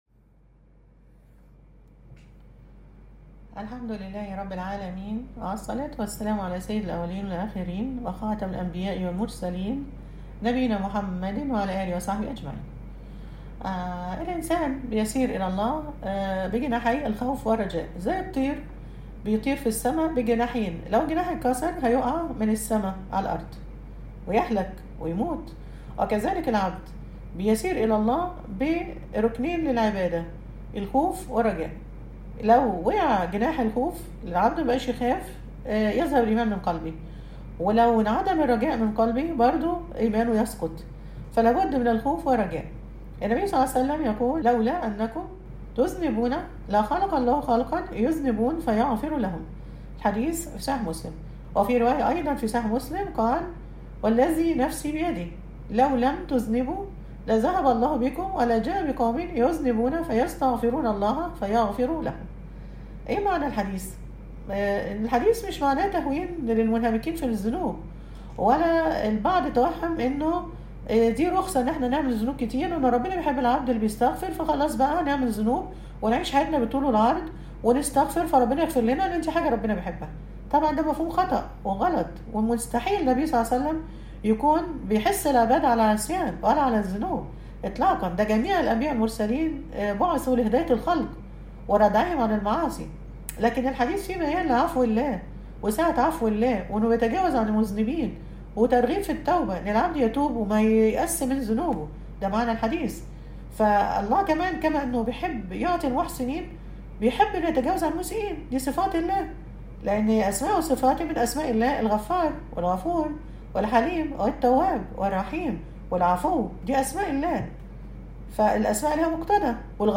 المحاضرة الرابعة- “الرجاء”